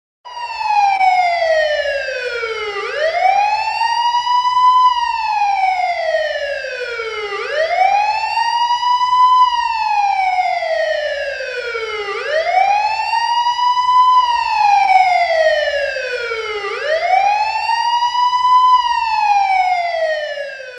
Police Car Siren (Sound Effect)
Category: Sound FX   Right: Personal